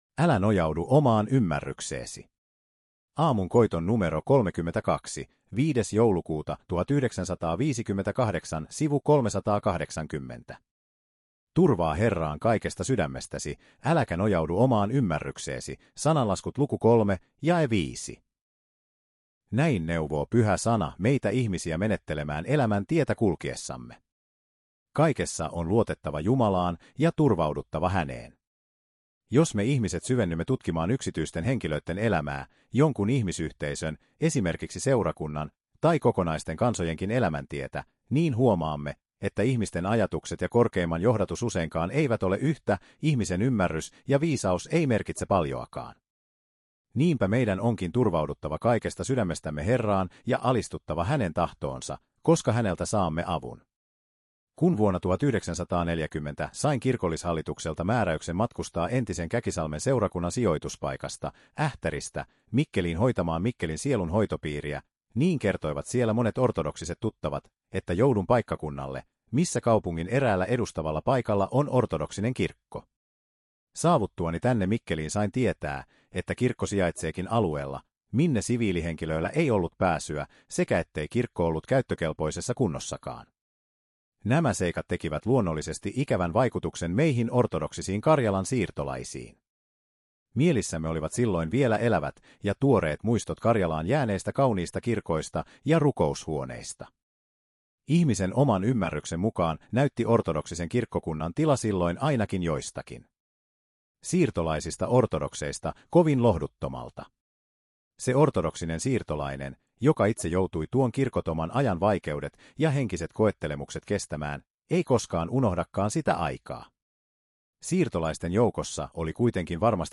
puhe